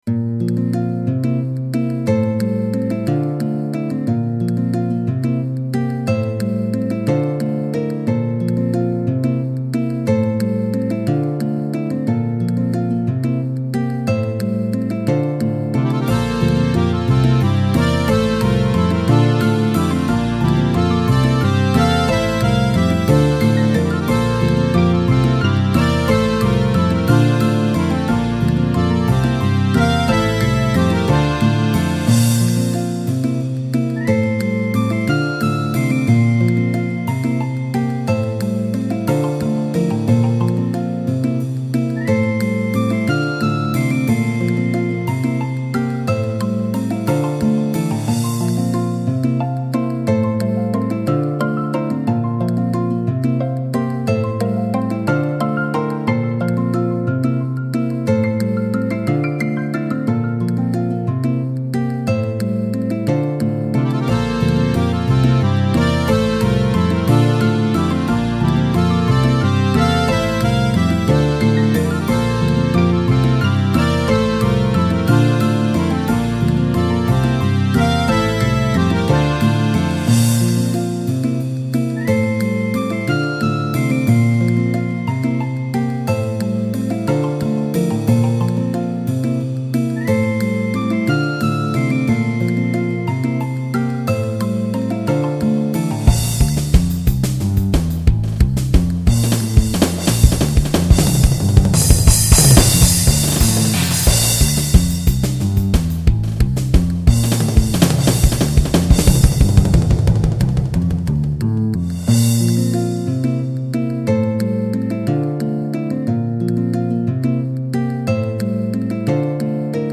ノイズも多少入ってます。
音源は基本的にハード音源のSc-8850です。
アコーディオンを試した。
しかしうるさい謎のドラムソロが入ってます。